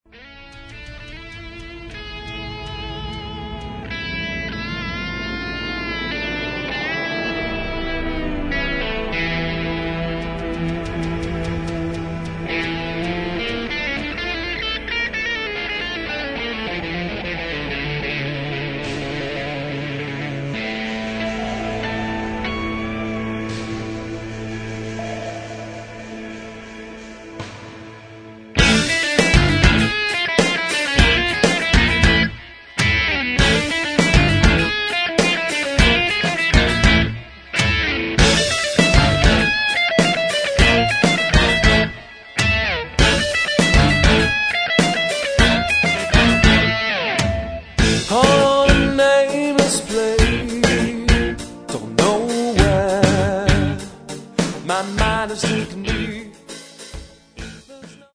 Metal
Очень нетрадиционный Progressive Metal.